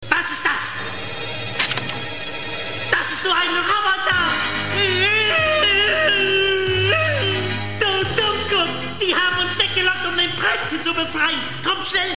Der Wutausbruch des Hexenmeisters ist einfach zu köstlich.